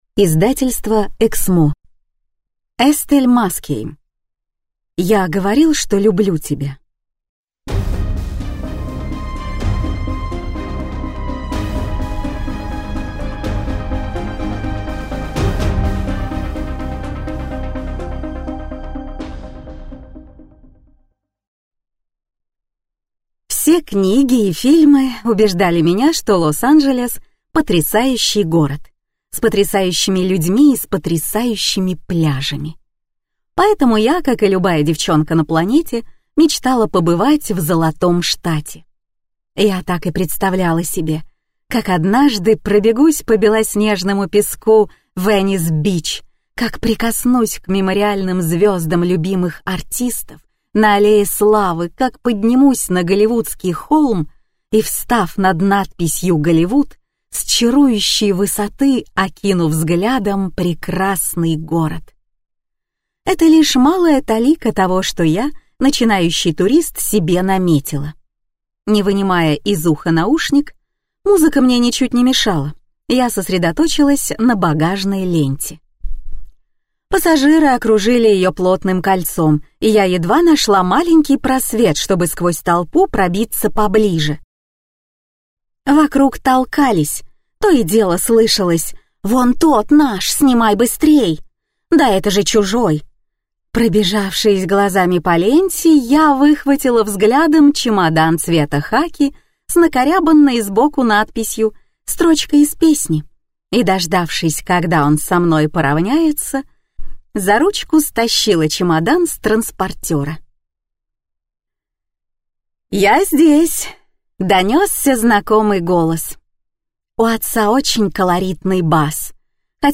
Аудиокнига Я говорил, что люблю тебя?